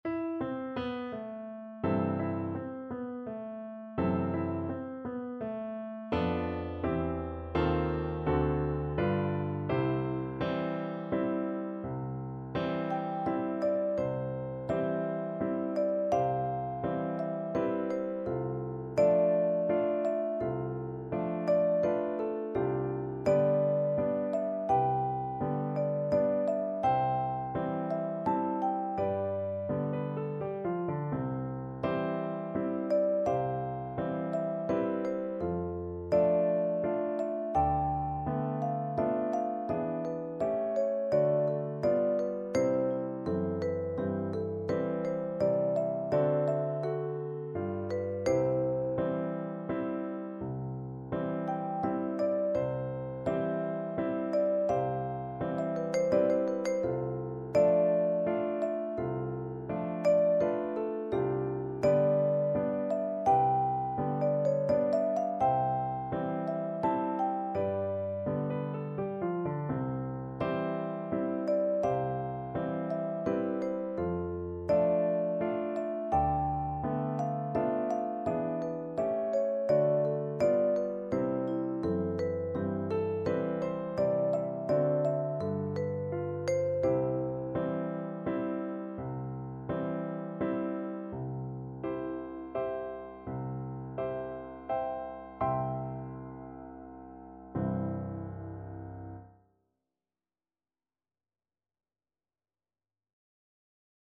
Vibraphone
3/4 (View more 3/4 Music)
= 84 Andante non troppe e molto maestoso
C major (Sounding Pitch) (View more C major Music for Percussion )
Classical (View more Classical Percussion Music)